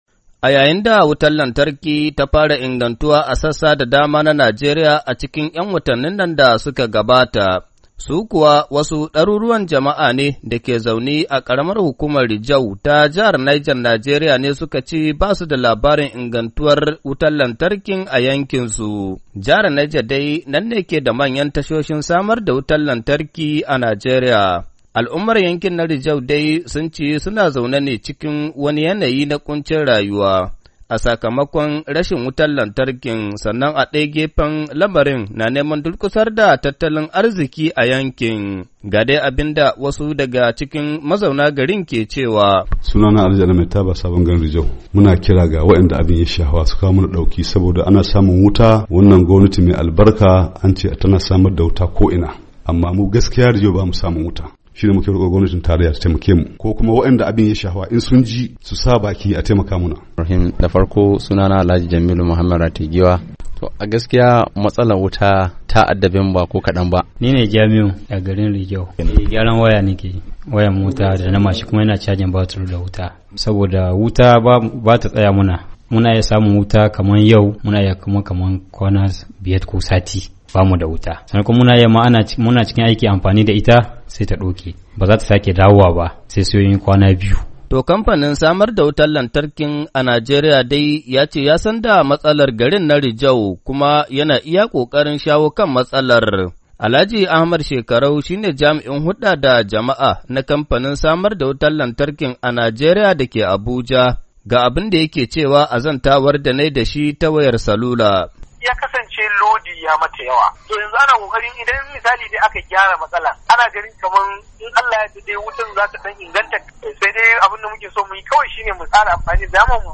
cikakken rahoton